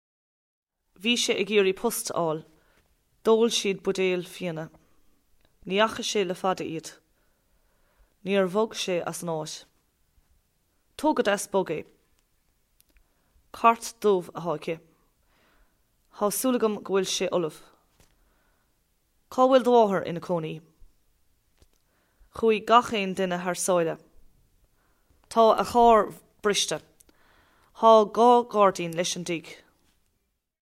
Sample sound files for Modern Irish
Each sample here is from the beginning of a recording in which the particular speaker read out a set of sentences containing tokens of the lexical sets devised for the phonology of modern Irish.
Sean_Phobal_(F_45)_S.wav